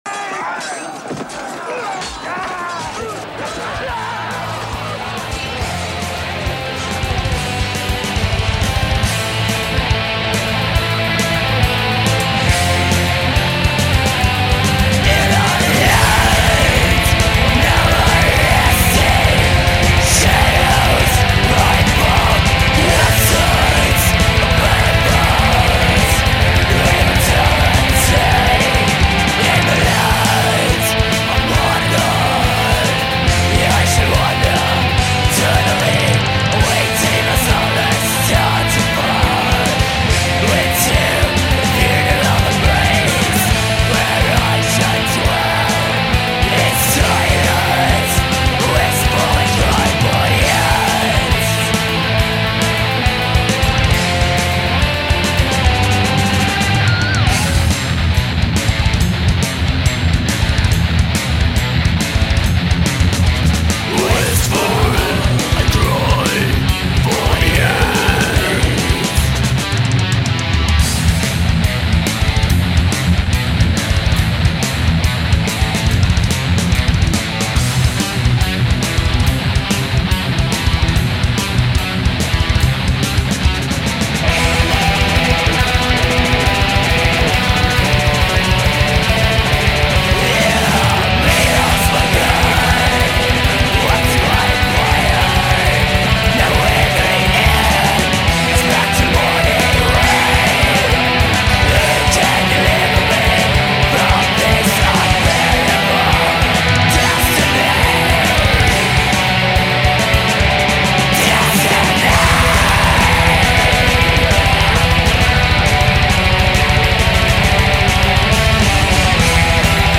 Black Metal